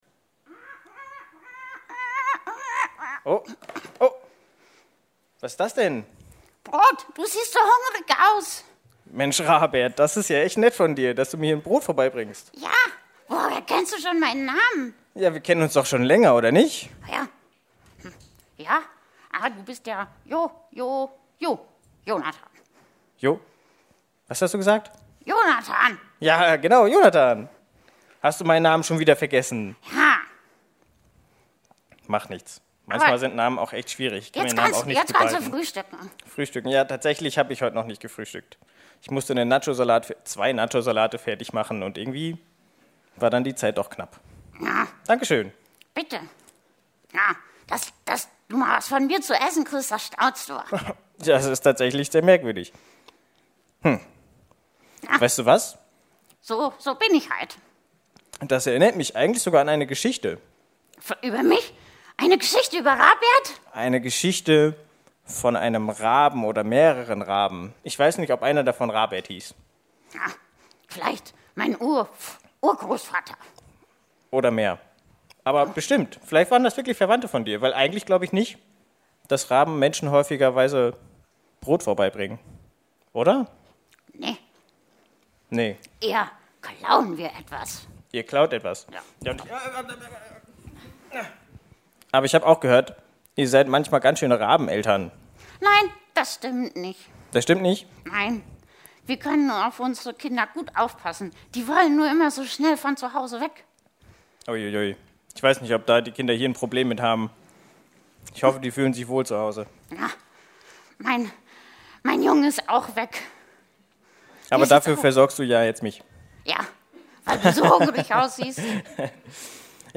familiengottesdienst-zum-erntedankfest.mp3